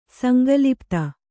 ♪ sanga lipta